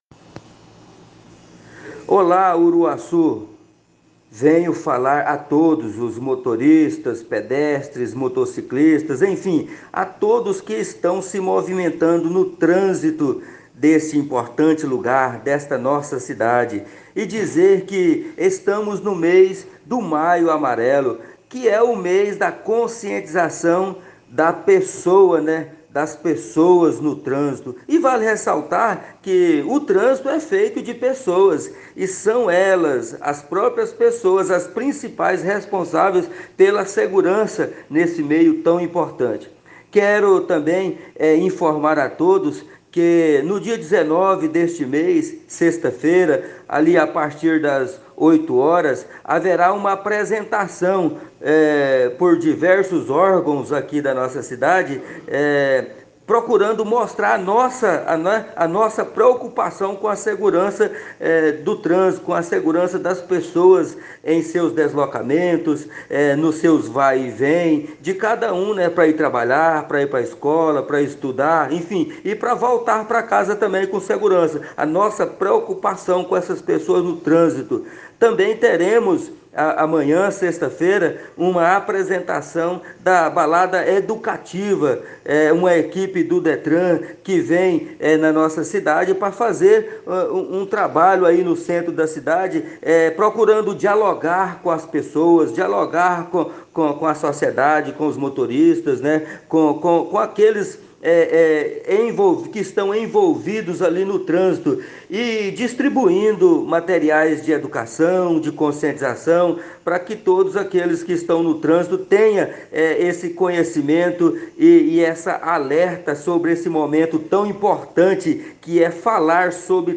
Tavares Amigo10, superintendente municipal de Trânsito de Uruaçu, presta informações e orienta usuários do trânsito.